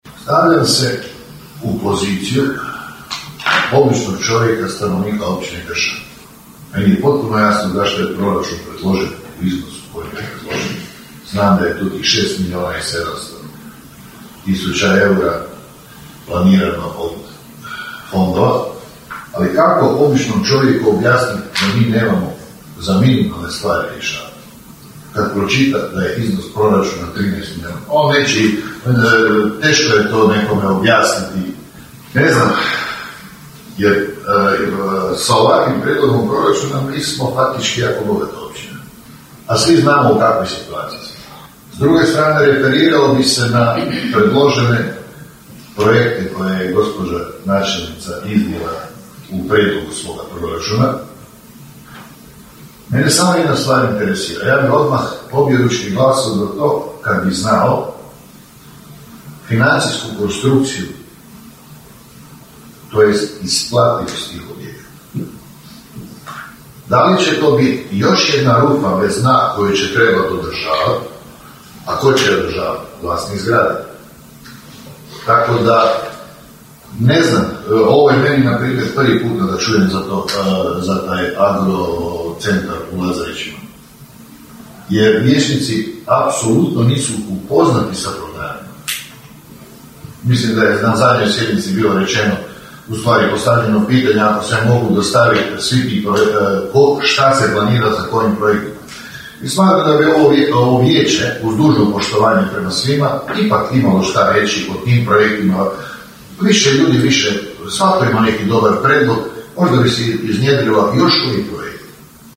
Proračun Općine Kršan za iduću godinu planiran je u visini od 13.807,882 eura, rečeno je sinoć na sjednici Općinskog vijeća na prvom čitanju proračuna.
U raspravi je vijećnik SDP-a Silvano Uravić primijetio: (